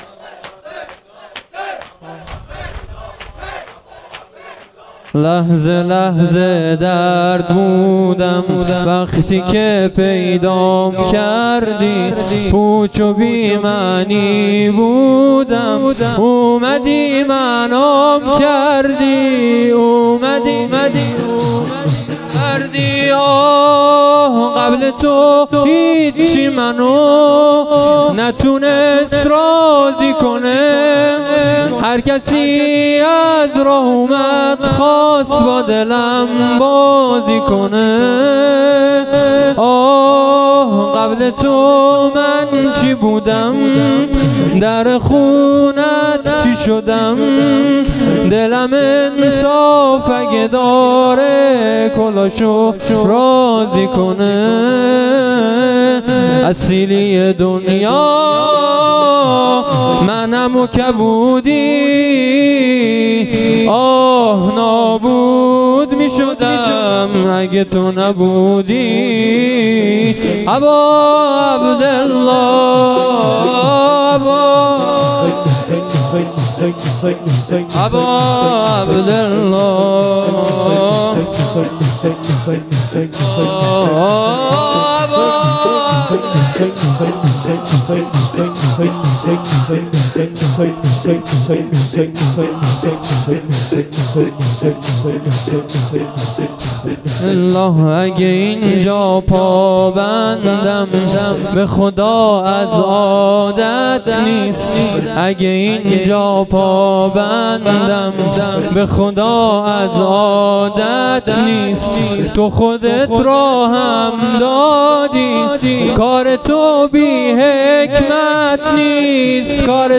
شور
مراسم عزاداری وفات حضرت زینب (س) ۱۴۰۰